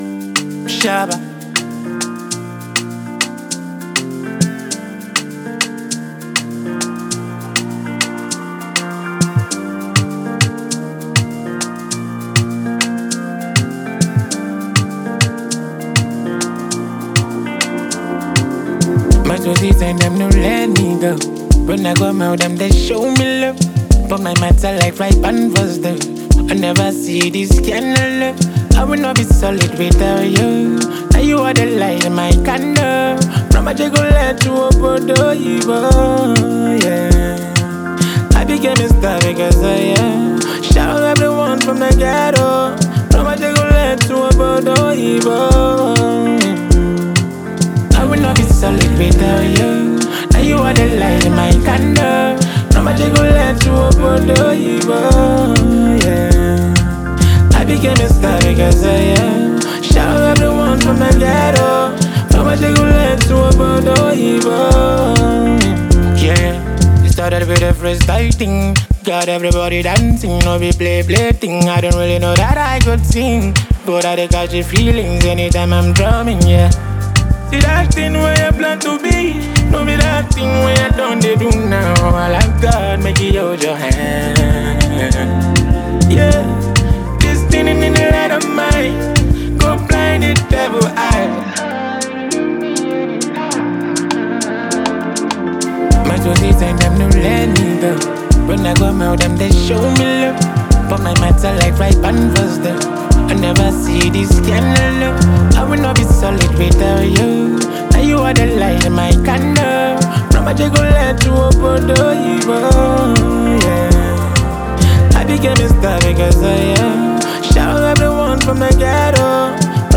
melodic beats
With heartfelt lyrics and infectious rhythm